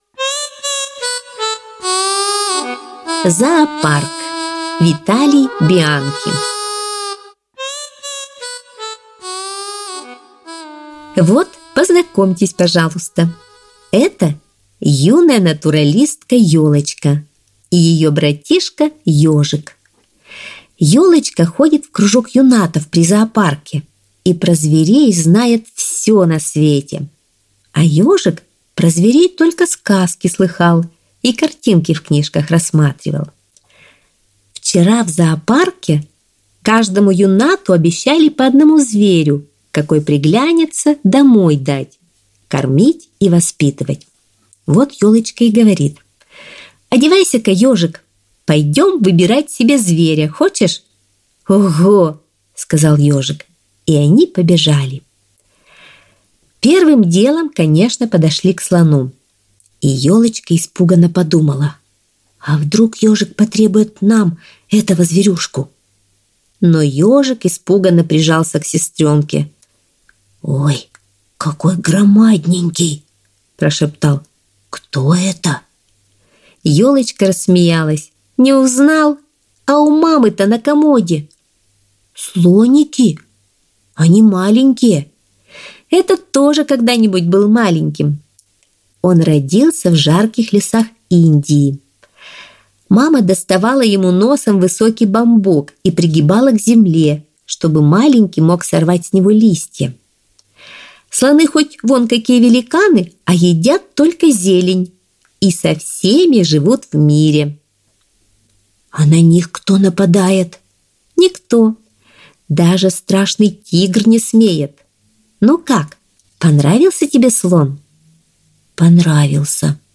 Зоопарк - аудио рассказ Бианки - слушать онлайн